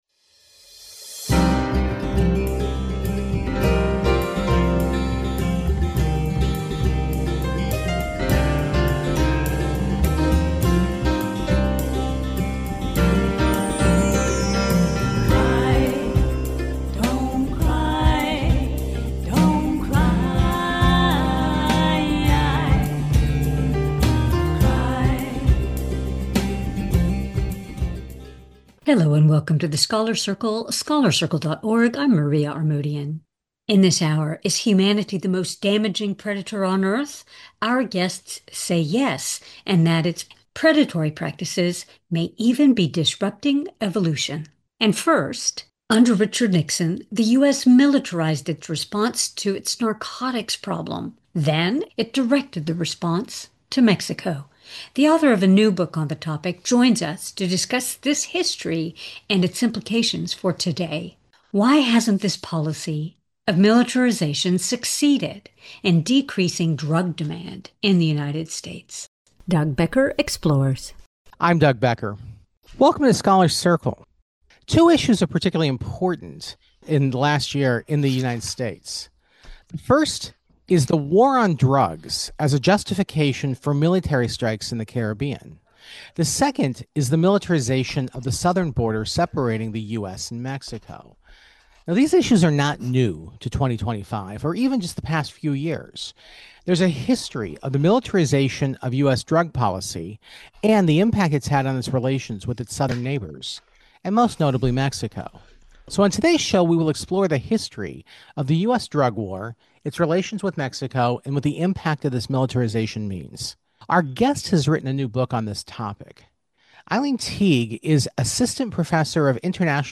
Scholars’ Circle – Book Author interview : Policing on Drugs – The United States , Mexico and Origins of Modern Drug War, 1996 – 2000 – December 7, 2025 | The Scholars' Circle Interviews